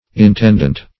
Intendent \In*tend"ent\, n.